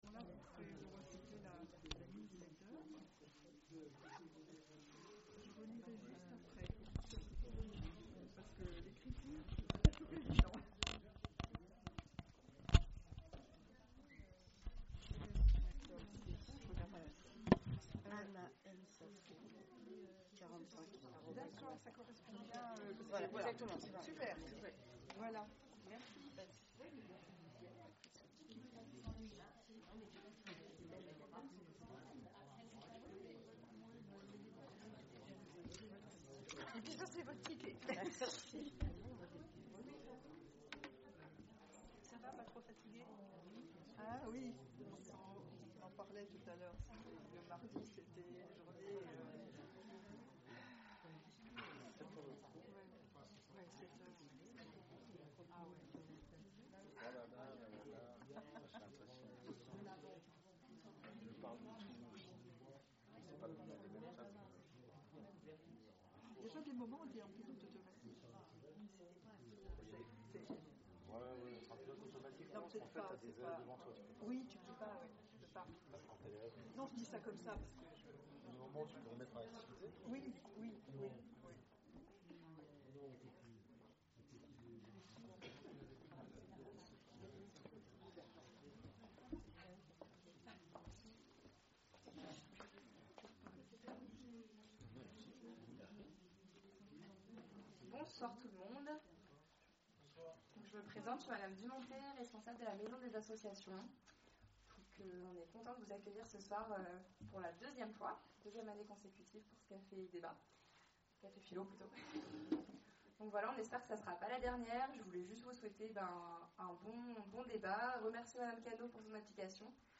Conférences et cafés-philo, Orléans